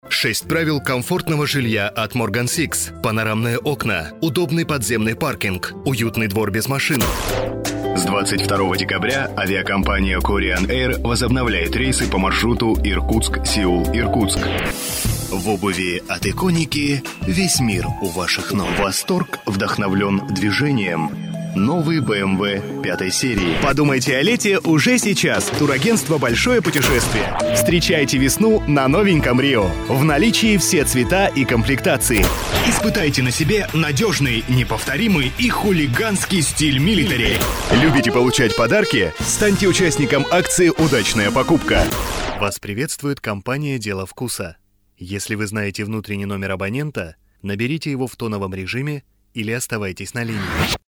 Диктор - информационно-деловой голос, опыт в озвучании - более 10 лет.
Тракт: звуковая карта - Fireface UC 400 RME предусилитель - Eureka (class - A) микрофон - SM7A
Демо-запись №1 Скачать